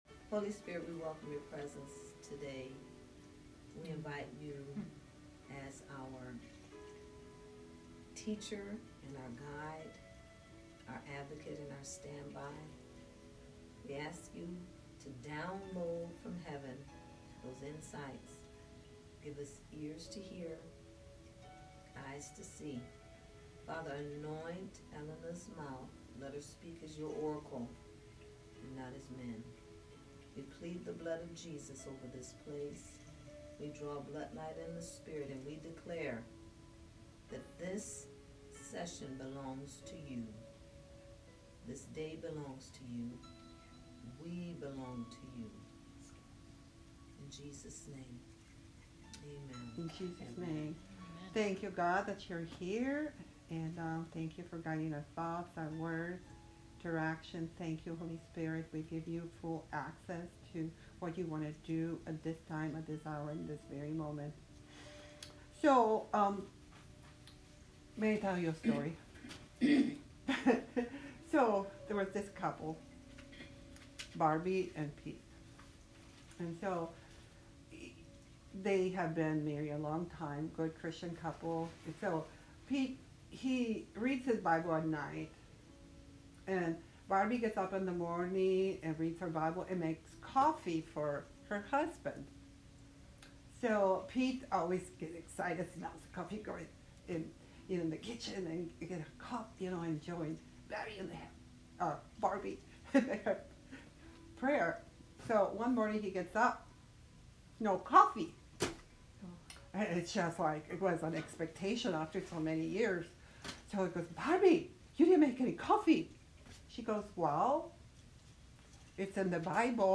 Adventures In The Miraculous Service Type: Class Join us for week 1 of Adventures In The Miraculous Sunday School.